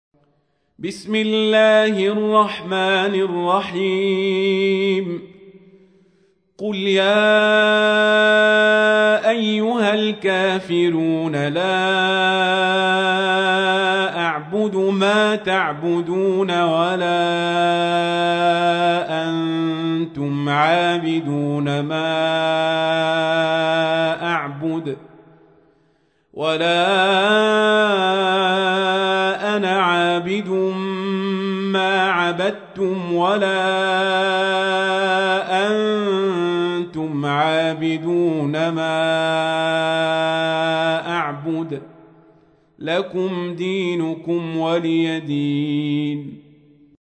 تحميل : 109. سورة الكافرون / القارئ القزابري / القرآن الكريم / موقع يا حسين